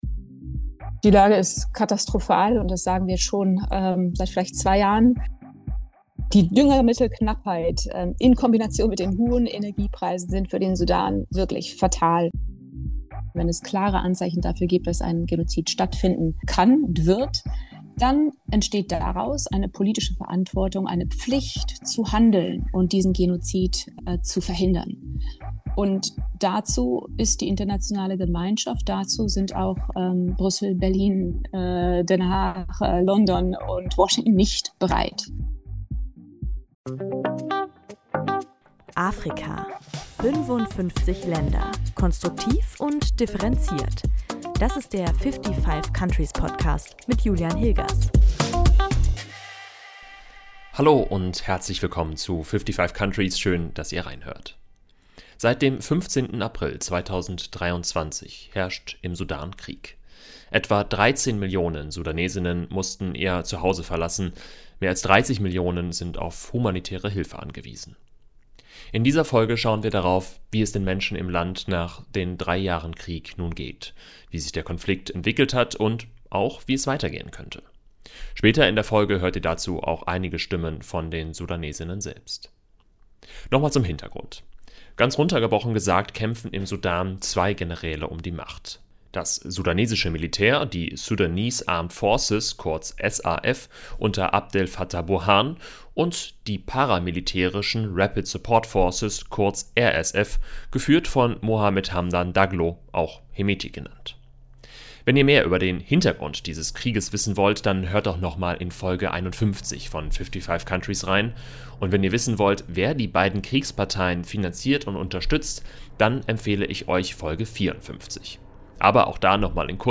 In dieser Folge schauen wir darauf, wie es den Menschen im Land nach drei Jahren Krieg geht, wie sich der Konflikt entwickelt hat und wie es weiter gehen könnte. Später in der Folge hört ihr dazu auch einige Stimmen von den Sudanes:innen selbst.